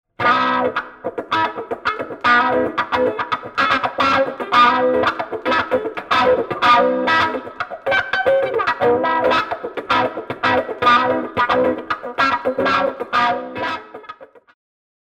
BEHRINGER ( ベリンガー ) >HB01 Hellbabe ワウペダル｜サウンドハウス